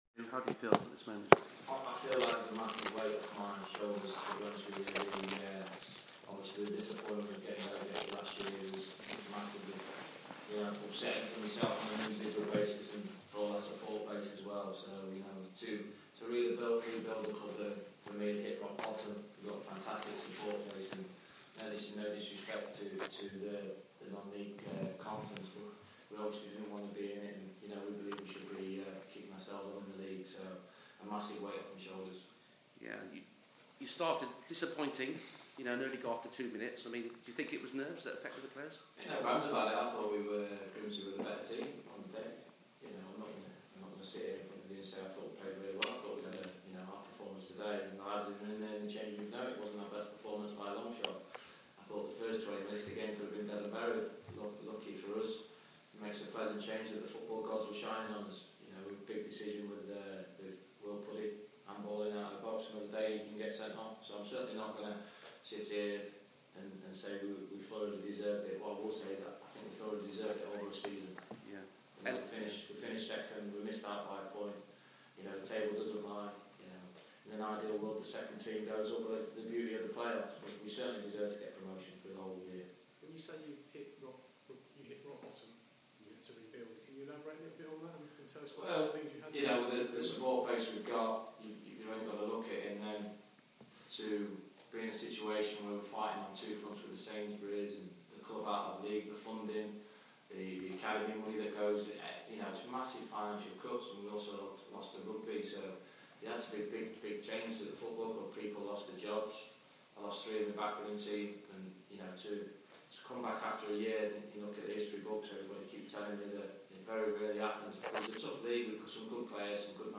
Darrell Clarke Press conference
Darrell Clarke speaks to the media after his Bristol Rovers side beat Grimsby Town on penalties to win promotion back to the football league